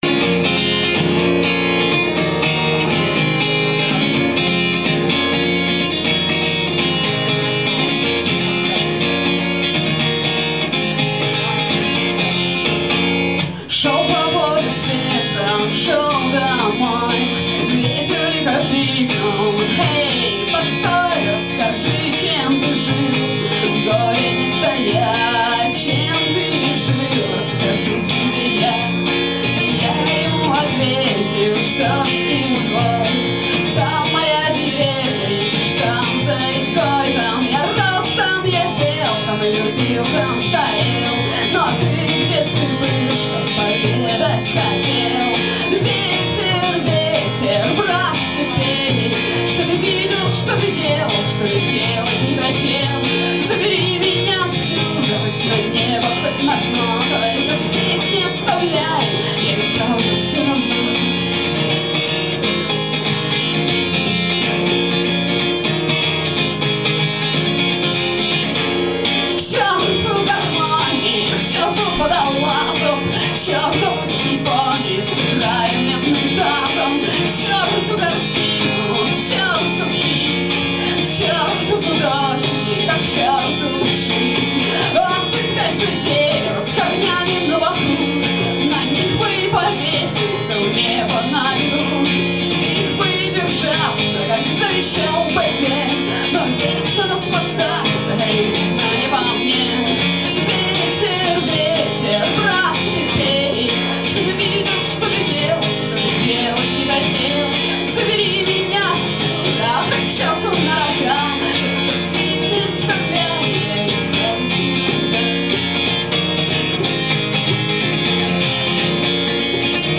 Многопесеньё шаманско-древнерусское в Uбkе!